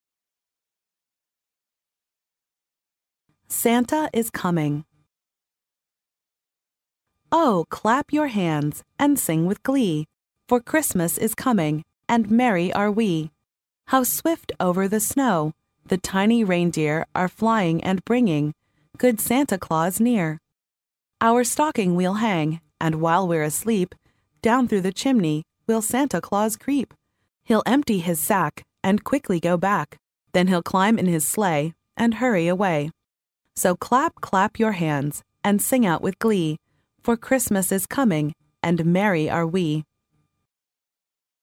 幼儿英语童谣朗读 第22期:圣诞老人来了 听力文件下载—在线英语听力室